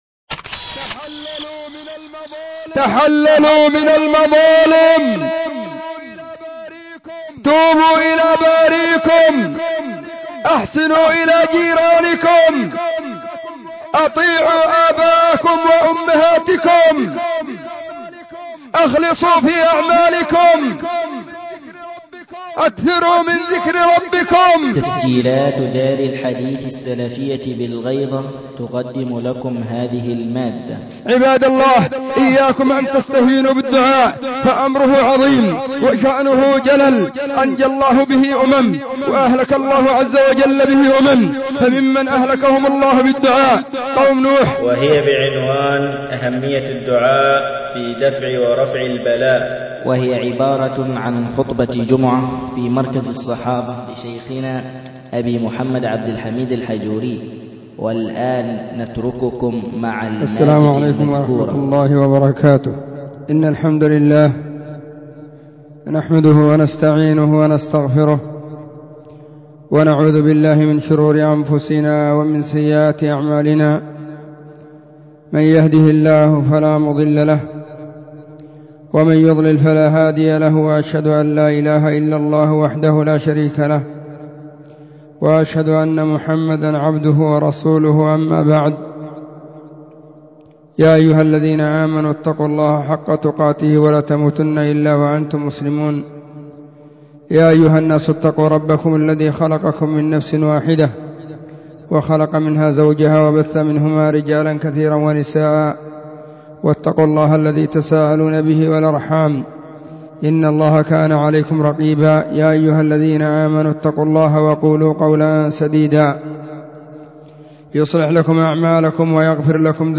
خطبة جمعة بعنوان : اهمية الدعاء في دفع ورفع البلاء.
📢 وكانت في مسجد الصحابة بالغيضة، محافظة المهرة – اليمن.